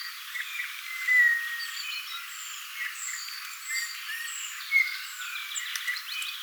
ihan kuin puukiipijä matkisi keltanokkarastaan ääniä?
mita_tuo_on_ihan_kuin_puukiipija_matkisi_keltanokkarastaan_aania.mp3